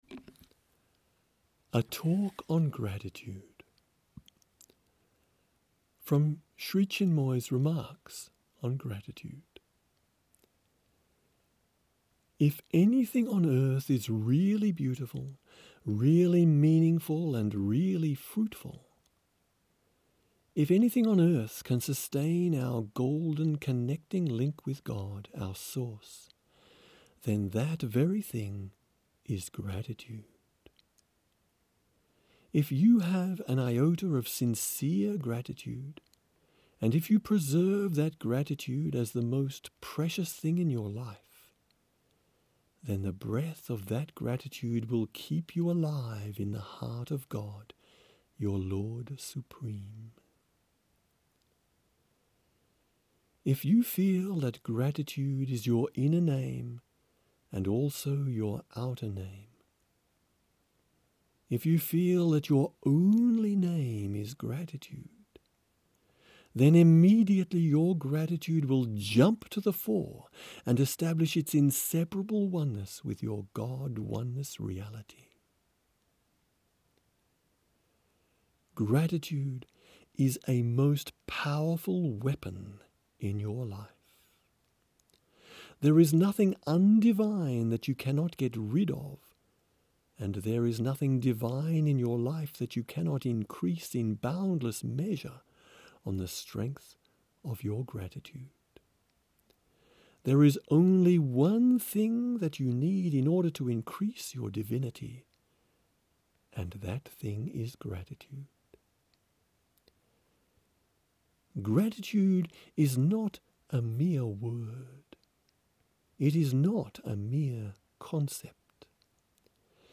463: A Talk on Gratitude